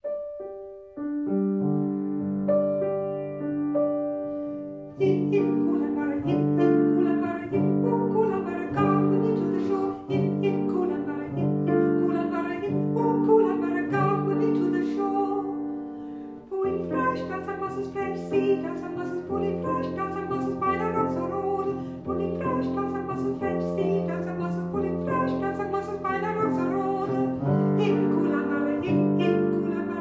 Gesang
Klavier
Arbeitslieder